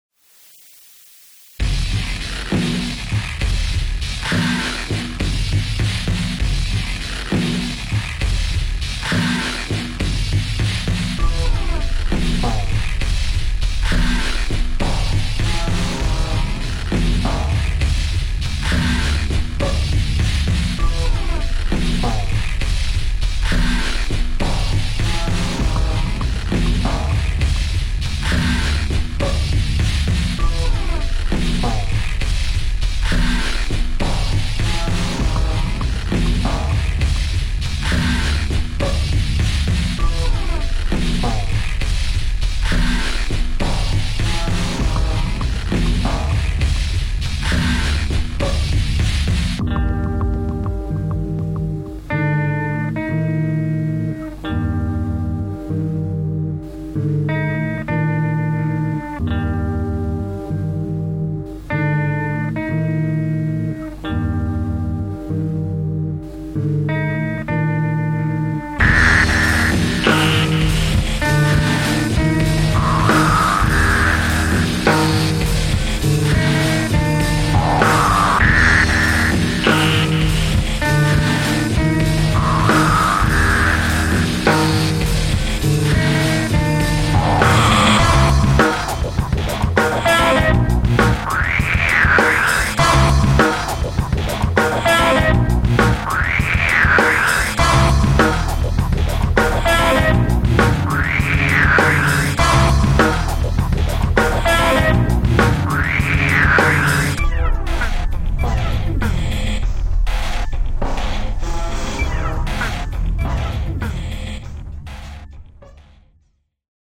Remix-Spaß